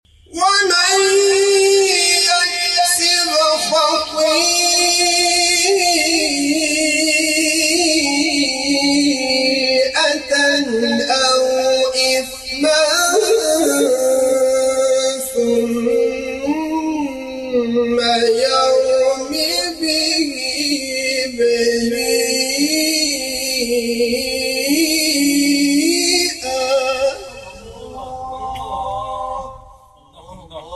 گروه شبکه‌های اجتماعی: فرازهای صوتی از تلاوت قاریان ممتاز و بین المللی کشور که در شبکه‌های اجتماعی منتشر شده است، می‌شنوید.